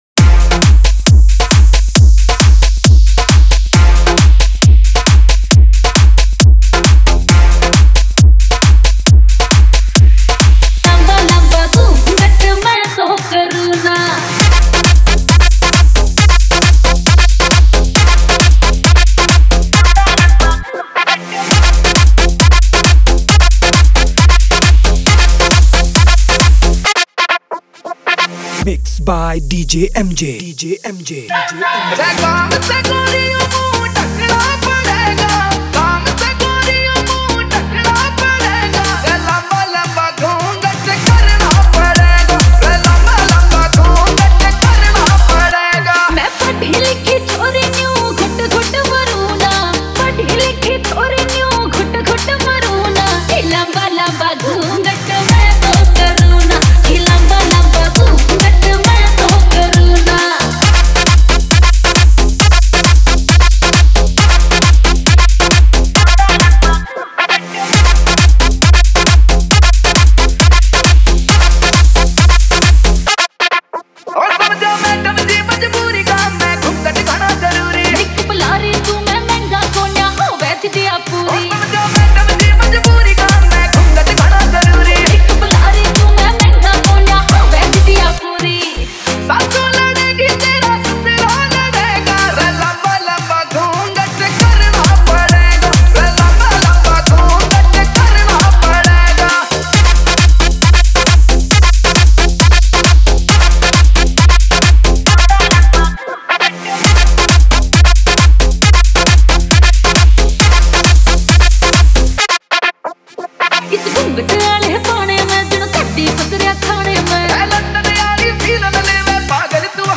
Haryanvi DJ Remix Songs